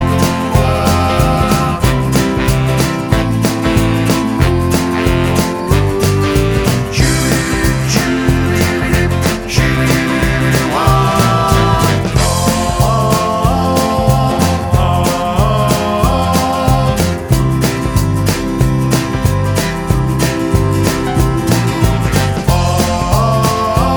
Lower Key Of C Rock 'n' Roll 2:57 Buy £1.50